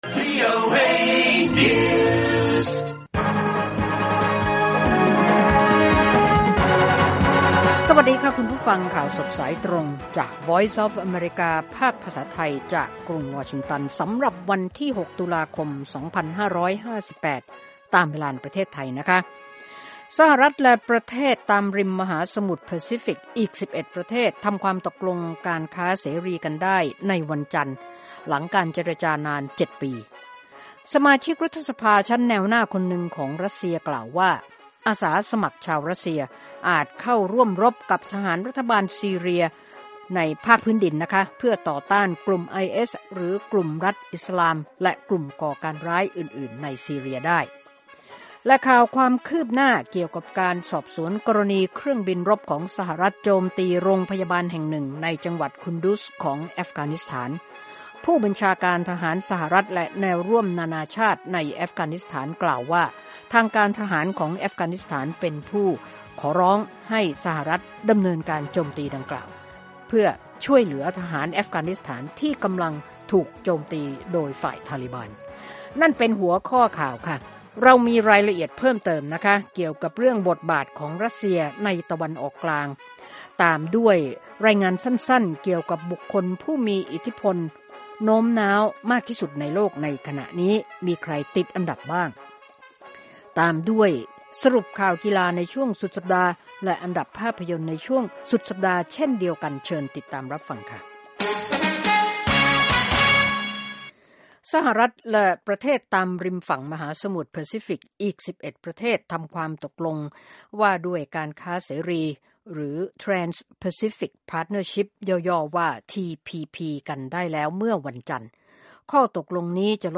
ข่าวสดสายตรงจากวีโอเอ ภาคภาษาไทย 6:30 – 7:00 น. วันอังคาร ที่ 6 ตุลาคม 2558